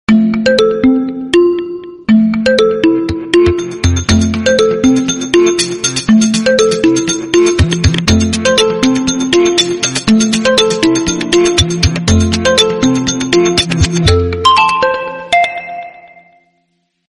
без слов приятные мелодичные